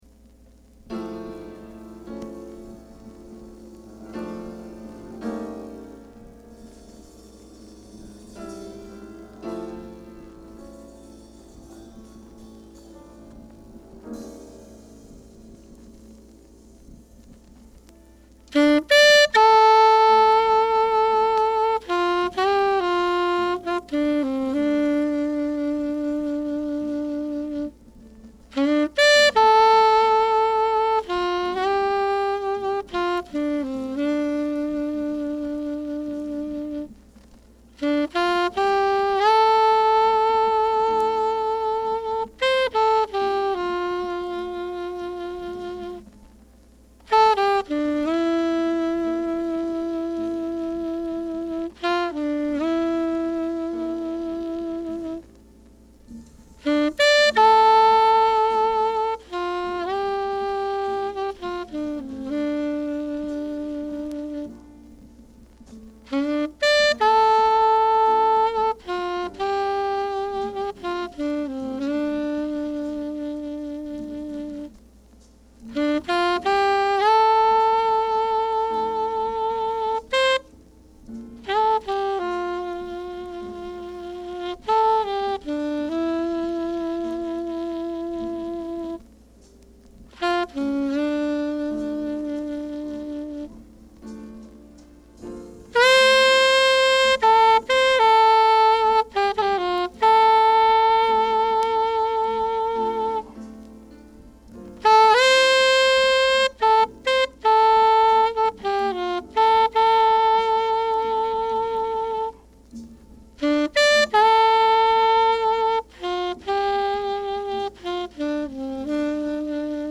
Release: 1960年録音 Label
Genre: Hard Bop / Piano Jazz Jacket